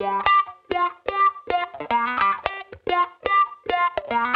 Index of /musicradar/sampled-funk-soul-samples/110bpm/Guitar
SSF_StratGuitarProc2_110G.wav